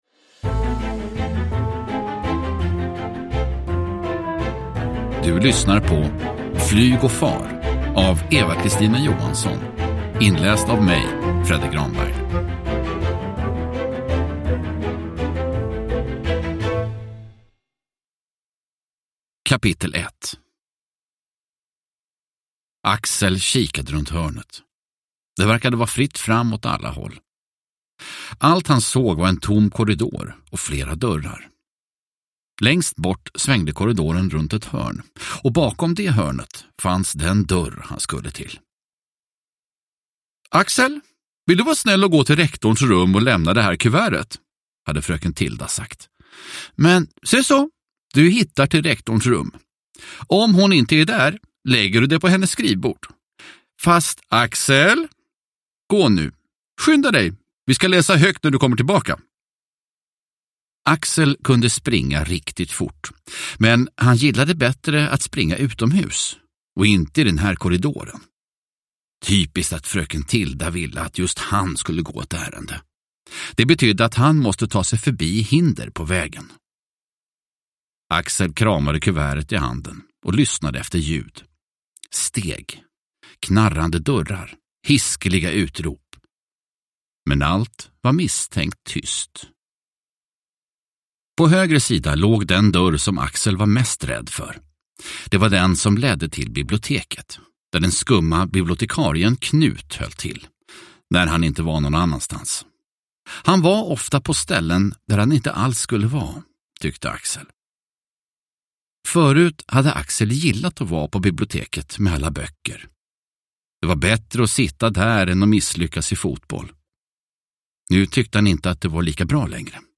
Flyg och far – Ljudbok – Laddas ner